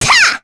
Sonia-Vox_Attack2_kr.wav